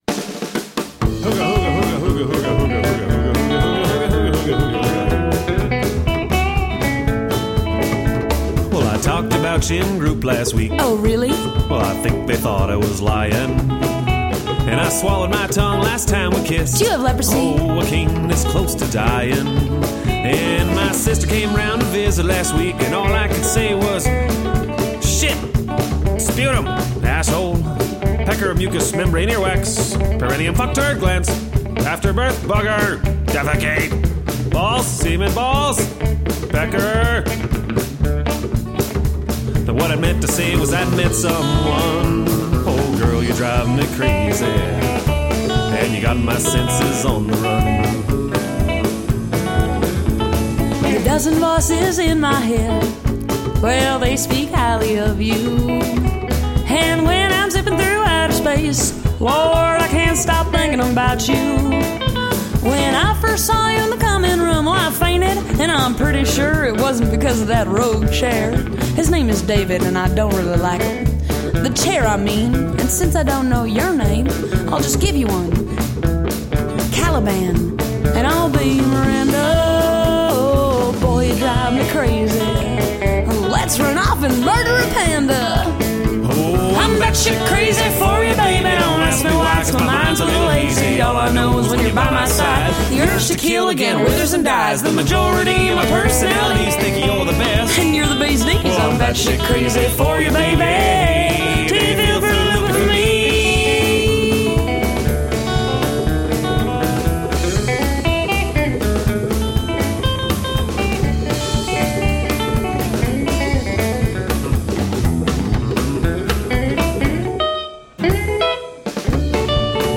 Country, rockabilly, western swing and lounge.
Tagged as: Alt Rock, Folk-Rock, Country